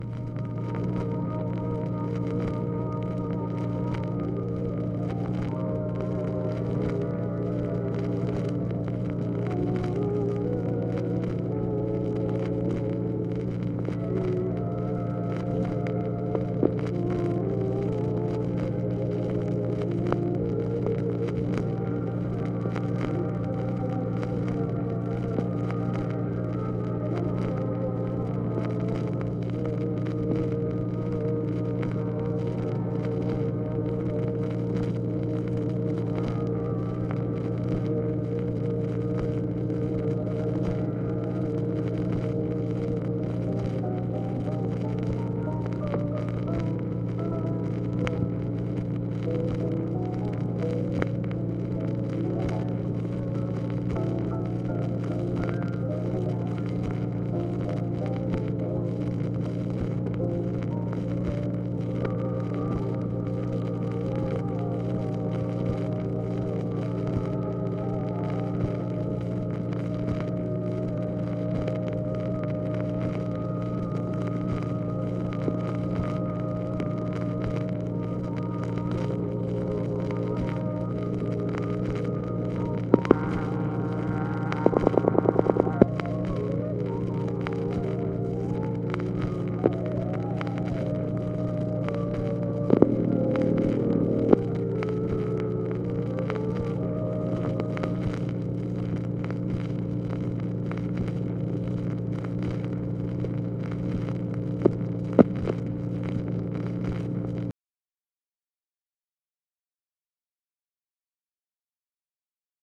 SOUNDS OF MUSIC PLAYING
OFFICE NOISE, February 20, 1965
Secret White House Tapes | Lyndon B. Johnson Presidency